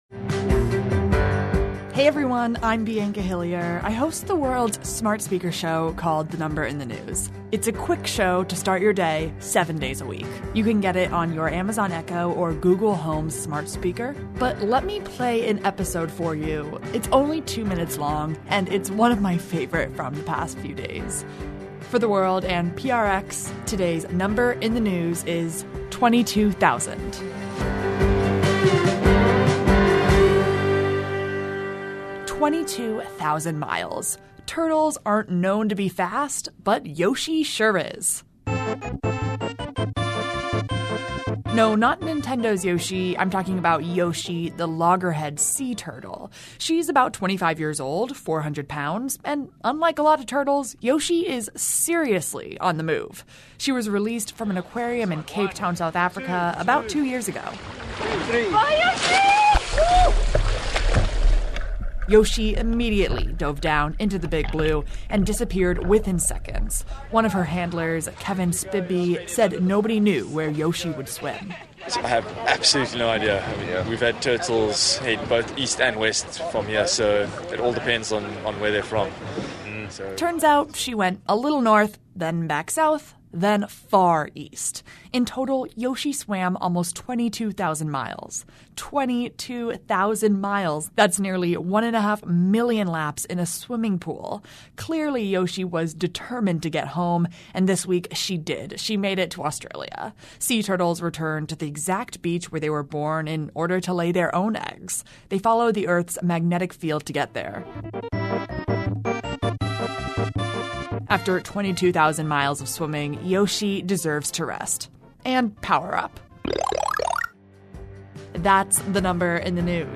Since then she has swum more than 22,000 miles from Cape Town to the western shores of Australia. The aquarium writes that Yoshi has traveled the longest-ever recorded journey of a tracked animal. In this week’s special feature of The Number in the News, hear from the people who released Yoshi into the Atlantic Ocean.